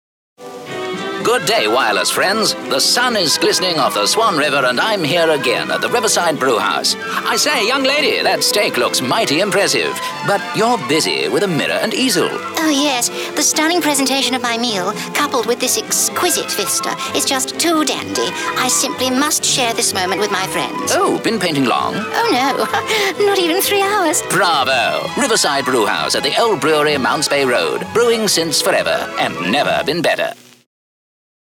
Radio Commercials
(British 1940s Newsreel Voice)